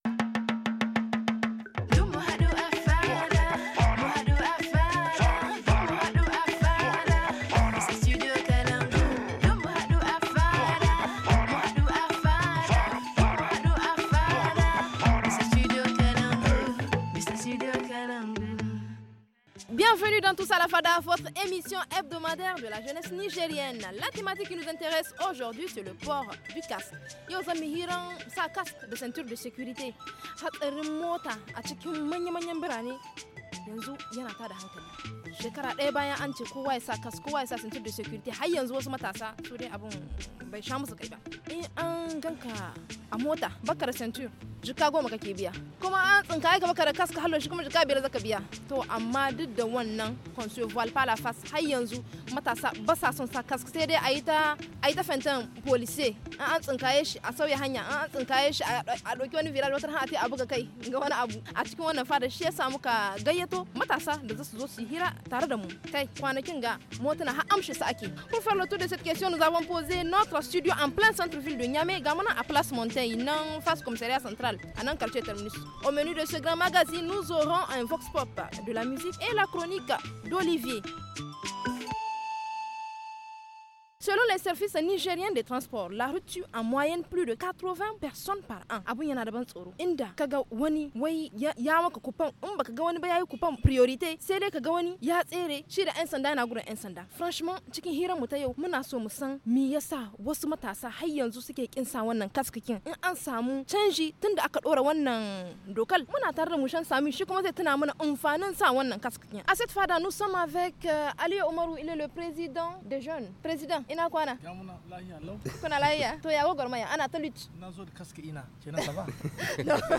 Pour faire le tour de ces questions nous avons posé notre studio en plein centre-ville de Niamey, à place Monteille juste en face du commissariat central.